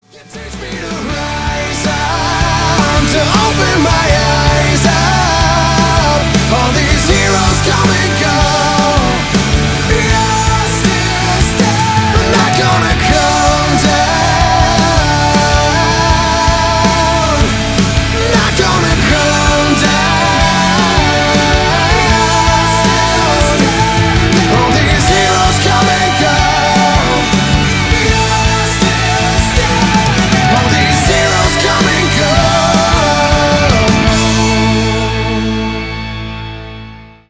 • Качество: 320, Stereo
мужской вокал
Alternative Rock
post-grunge
ballads